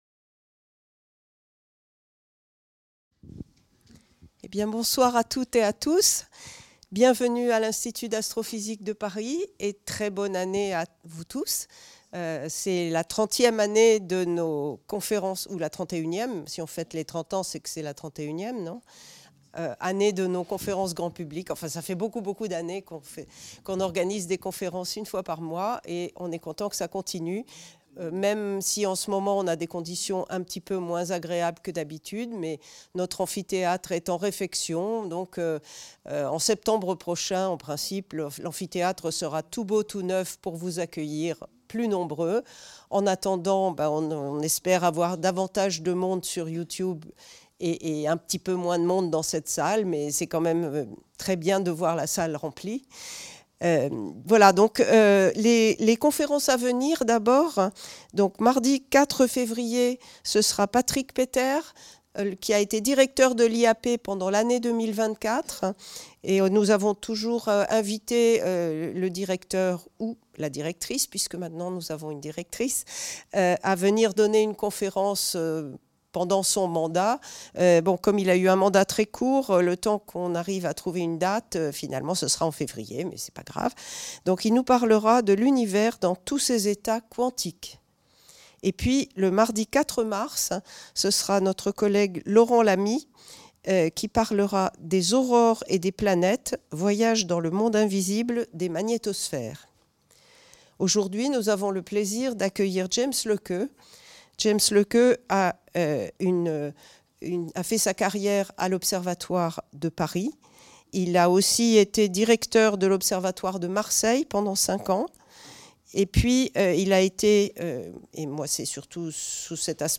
Cette conférence du cycle de conférences publiques de l'IAP a été donnée le 7 janvier 2025